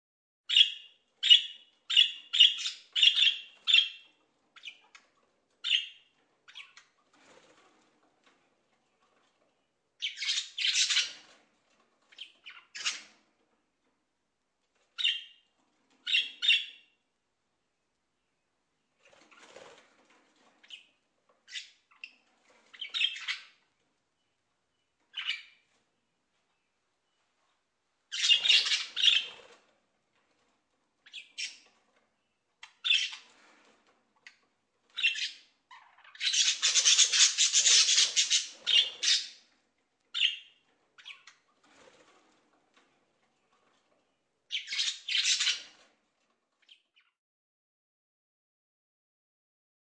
На этой странице собраны звуки, которые издают попугаи: от веселого чириканья до мелодичного пения.
Чириканье пернатого говоруна